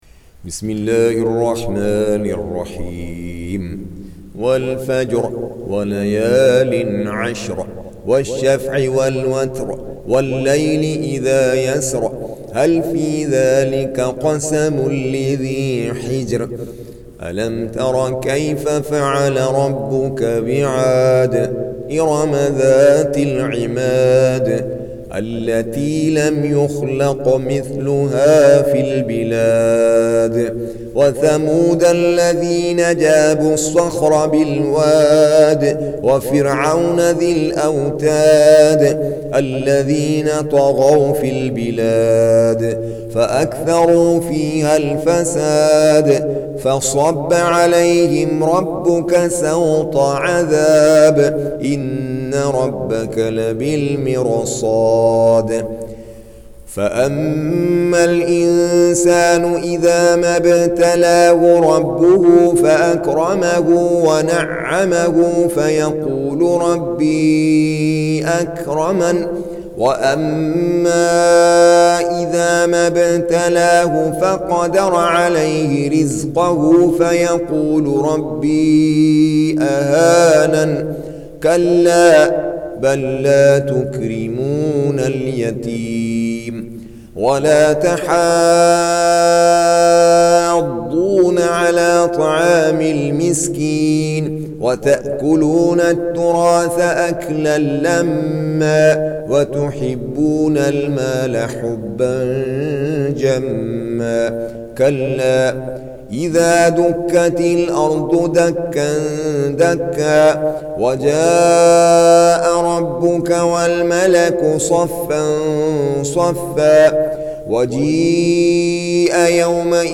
Surah Sequence تتابع السورة Download Surah حمّل السورة Reciting Murattalah Audio for 89. Surah Al-Fajr سورة الفجر N.B *Surah Includes Al-Basmalah Reciters Sequents تتابع التلاوات Reciters Repeats تكرار التلاوات